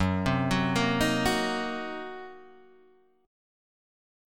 Gb6b5 chord